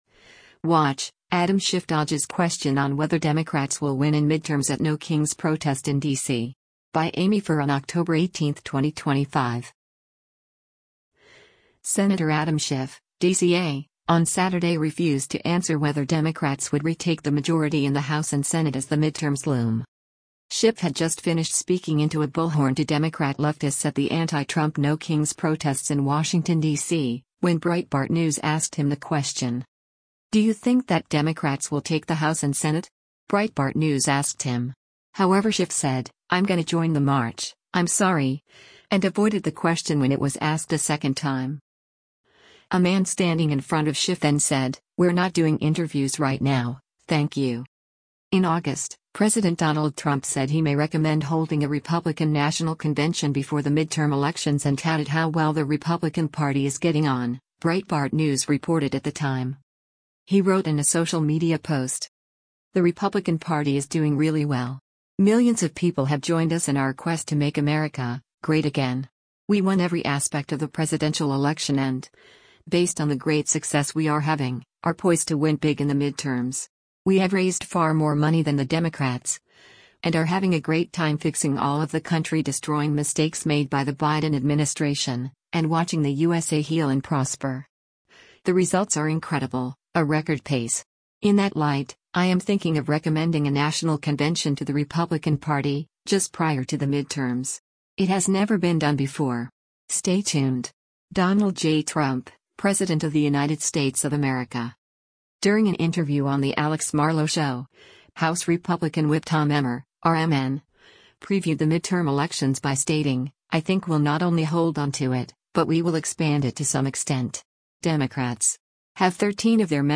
Schiff had just finished speaking into a bullhorn to Democrat leftists at the anti-Trump “No Kings” protests in Washington, DC, when Breitbart News asked him the question.
A man standing in front of Schiff then said, “We’re not doing interviews right now, thank you.”